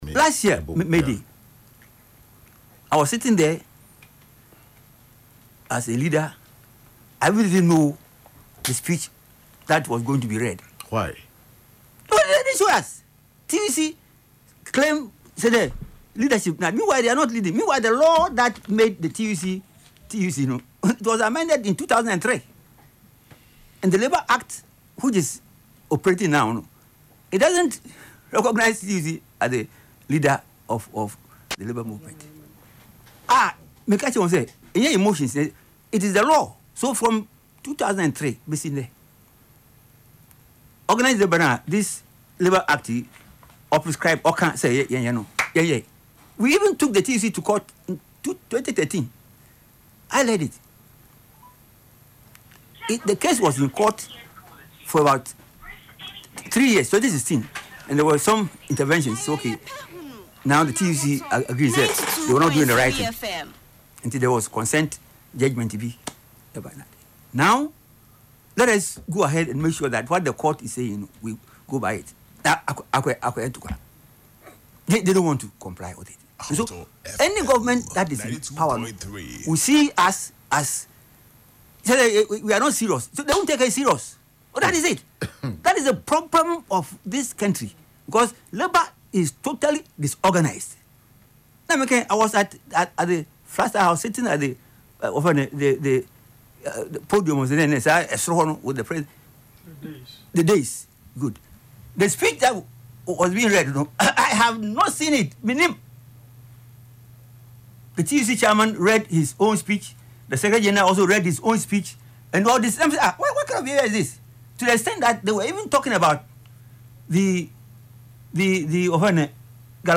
Speaking on Ahotor FM’s Yepe Ahunu programme on Saturday, April 25, 2026, he questioned the scope of the Planning Committee’s authority.